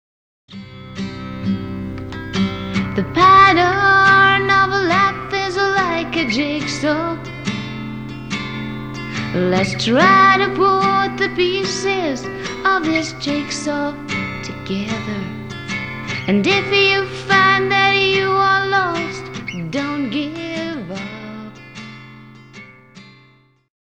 Samples are lower quality for speed.